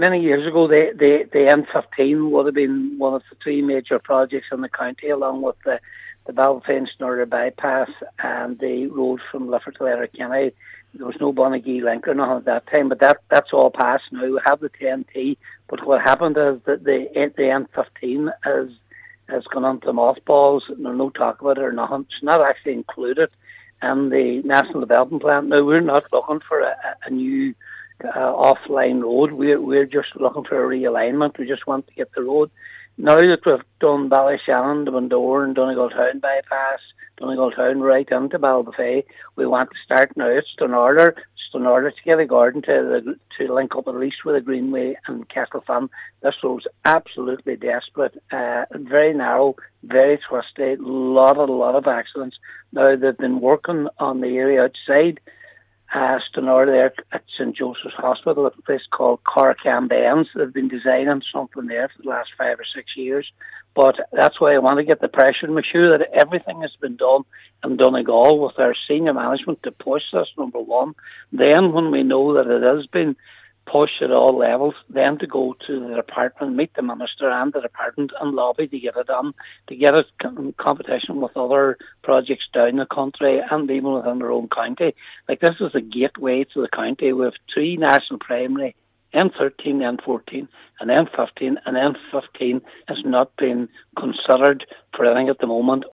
Cllr McGowan says what they’re asking for is reasonable………..